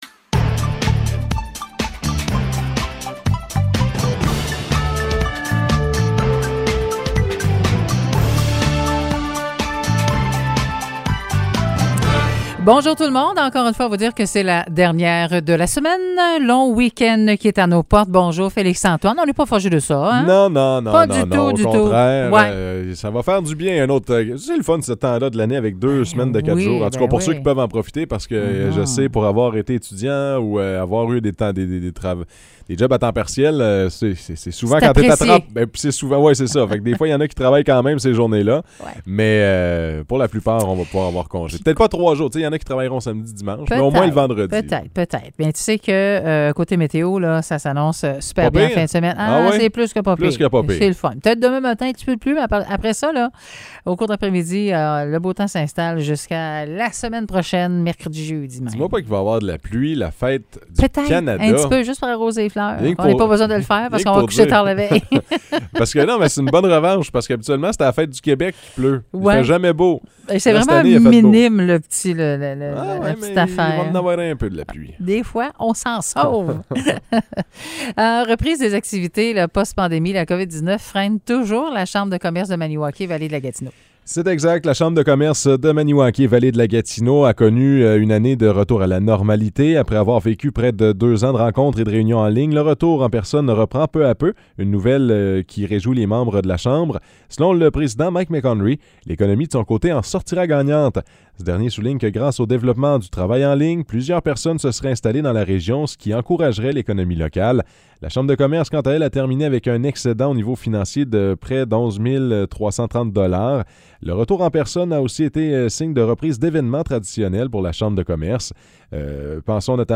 Nouvelles locales - 29 juin 2022 - 9 h